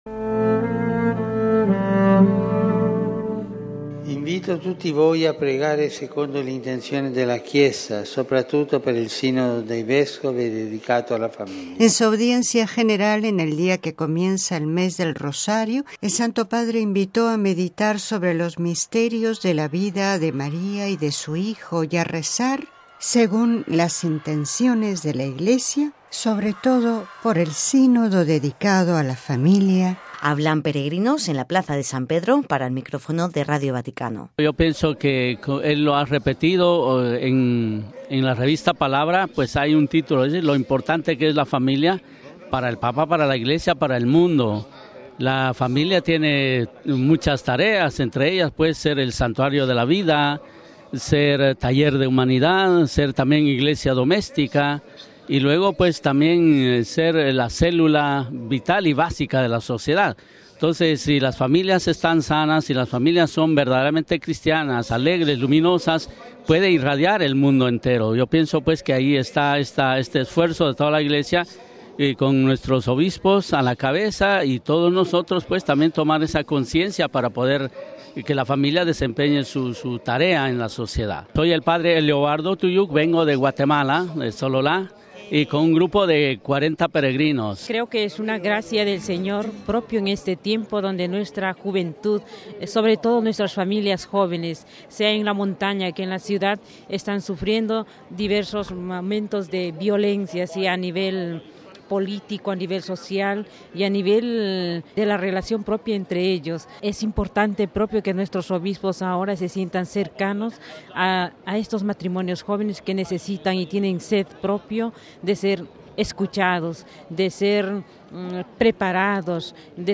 MP3 El Santo Padre en su Audiencia de este miércoles pidió a los fieles que rezarán por el Sínodo de los Obispos sobre la temática de la Familia que celebra en el Vaticano del 5 al 19 de octubre. Sobre este ‘gran evento de la Iglesia’, como el mismo Francisco lo definió, hablan en la Plaza de San Pedro peregrinos llegados de diferentes países del mundo.